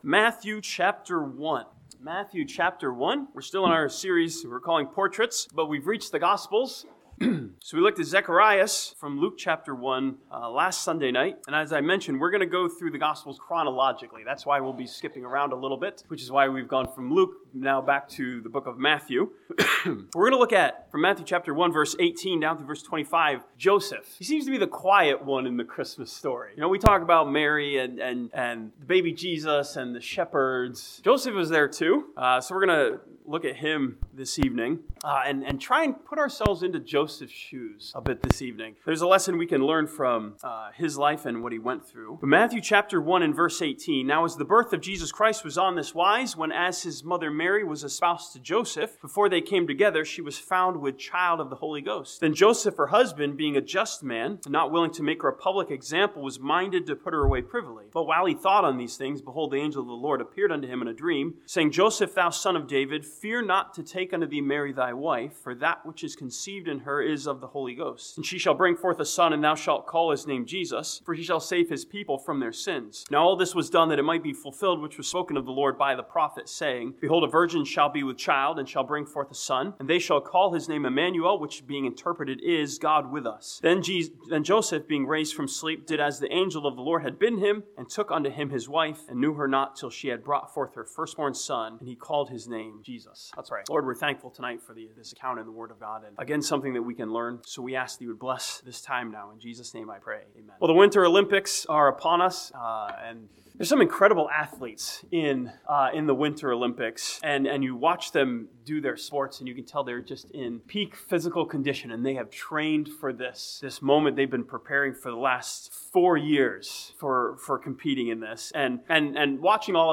This sermon from Matthew chapter 1 challenges us with Joseph's flexibility to trust God when his plans were disrupted.